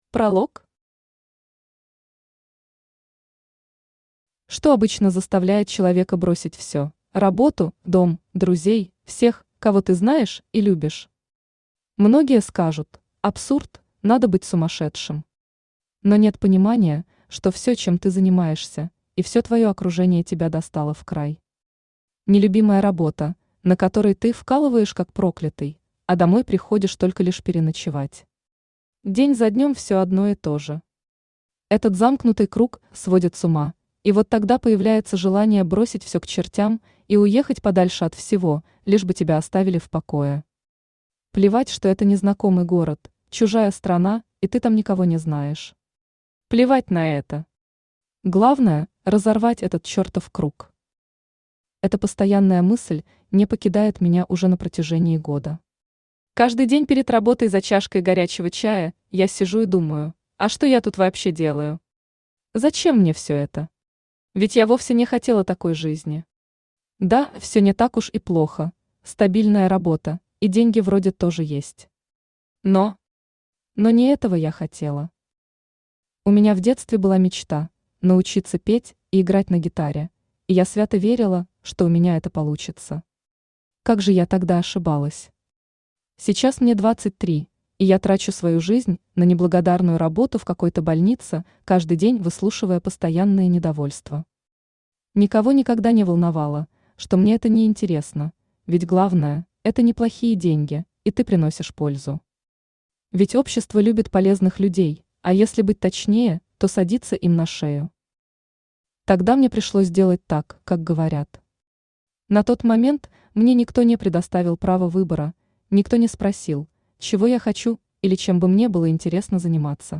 Аудиокнига Вторая жизнь | Библиотека аудиокниг
Aудиокнига Вторая жизнь Автор Анна Сергеевна Баканина Читает аудиокнигу Авточтец ЛитРес.